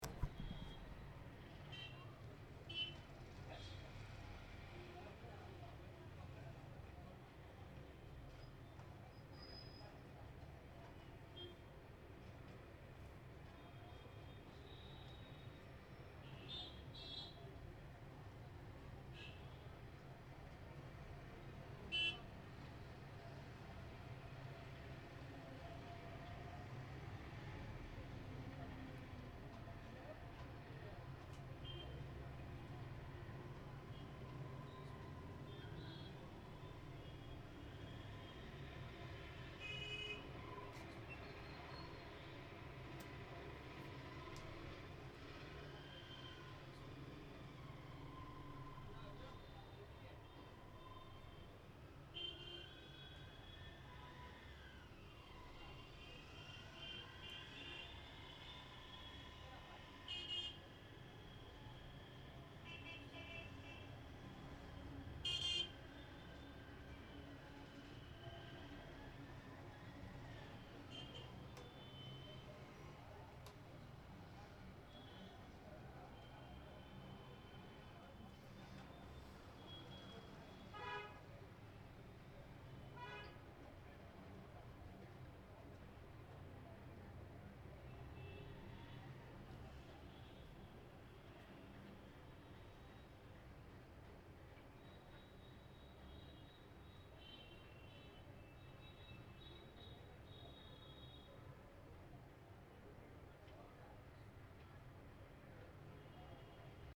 Je prends un moment pour dire au revoir au balcon de ma chambre.
ambiance_g.mp3